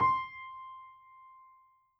Added more instrument wavs
piano_072.wav